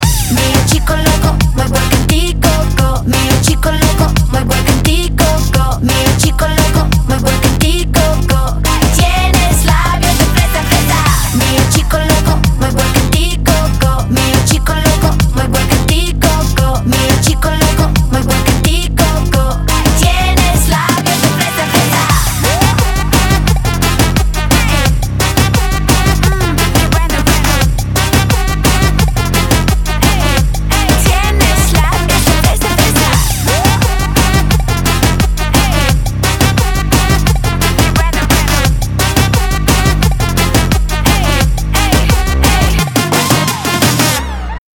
• Качество: 320, Stereo
поп
веселые
заводные
Веселий танцювальний рінгтончик